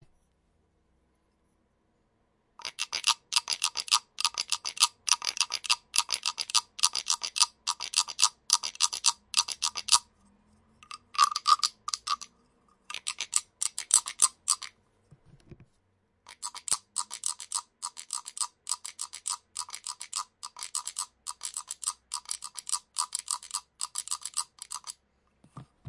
木制打击乐器青蛙3
描述：木打击乐青蛙
Tag: 青蛙 动物 非洲的 敲击